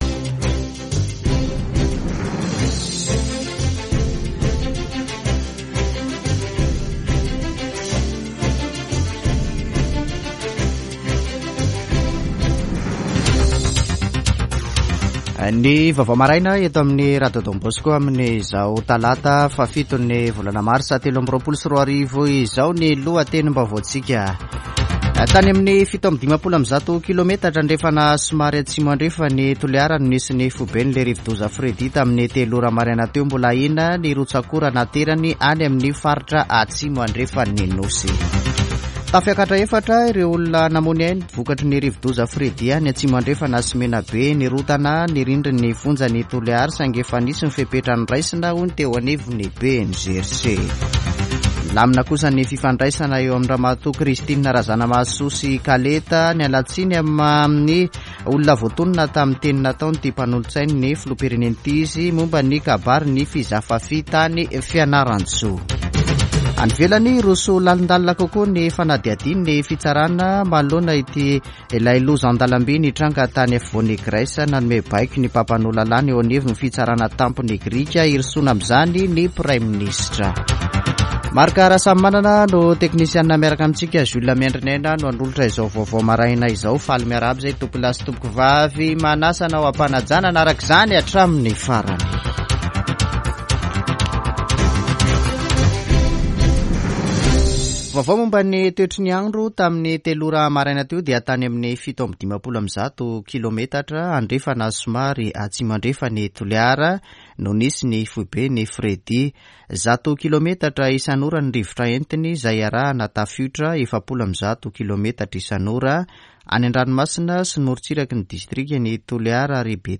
[Vaovao maraina] Talata 07 marsa 2023